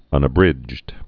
(ŭnə-brĭjd)